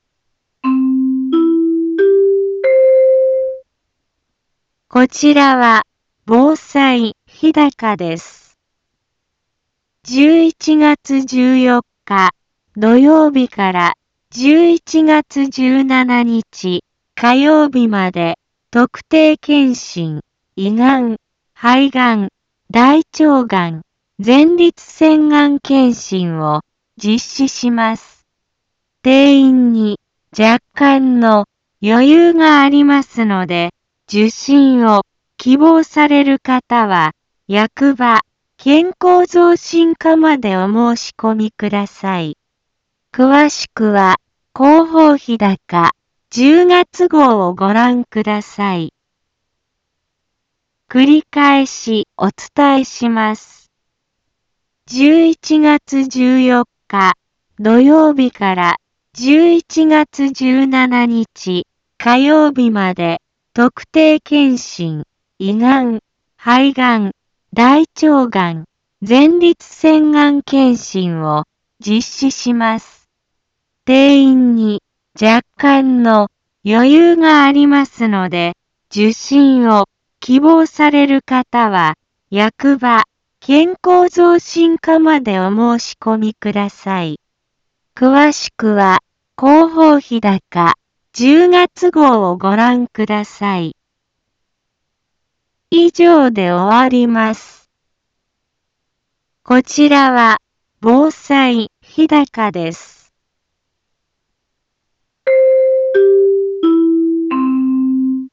一般放送情報
Back Home 一般放送情報 音声放送 再生 一般放送情報 登録日時：2020-10-09 15:03:50 タイトル：（日高町）特定検診・がん検診のお知らせ インフォメーション：こちらは、防災日高です。